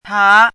chinese-voice - 汉字语音库
pa2.mp3